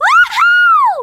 One of Princess Daisy's voice clips in Mario Kart: Double Dash!!